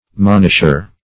Monisher \Mon"ish*er\, n. One who monishes; an admonisher.